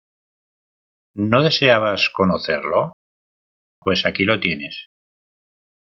Hyphenated as tie‧nes Pronounced as (IPA) /ˈtjenes/